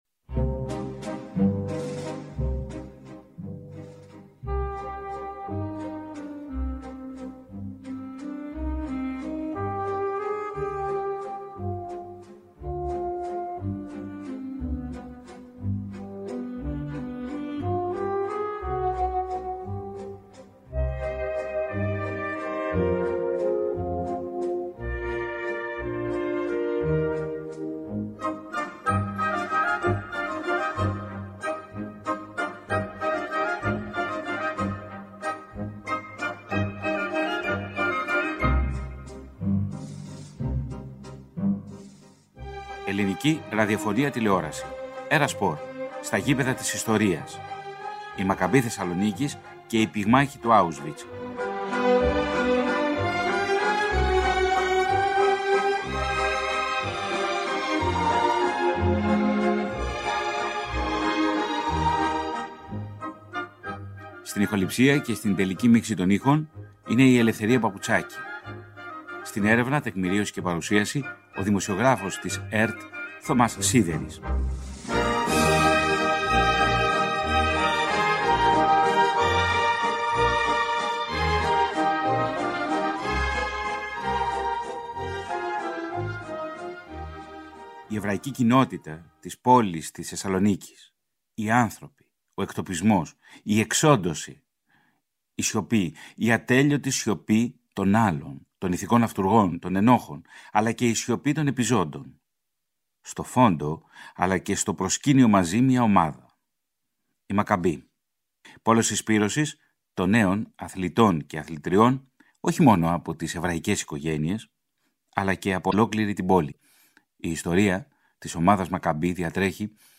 ΝΤΟΚΙΜΑΝΤΕΡ